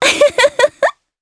Yuria-Vox_Happy2_jp.wav